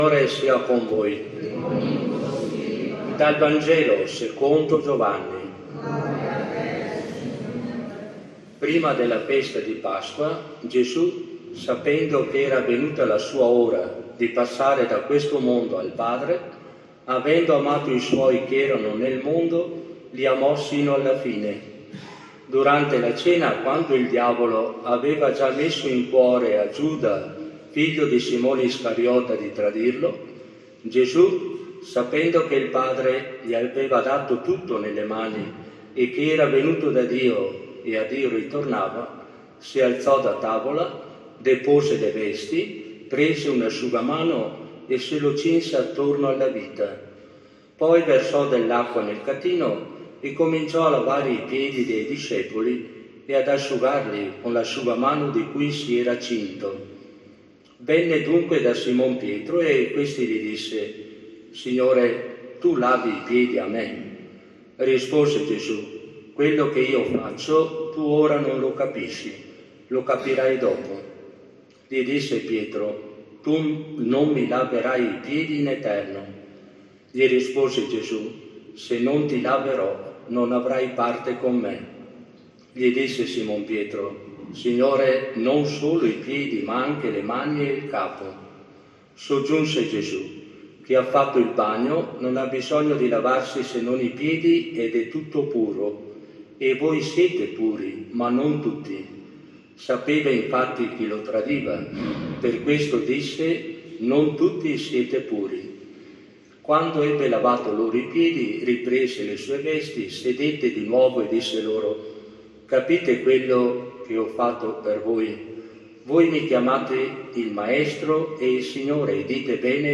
Omelia-Cena-Domuni-2025.mp3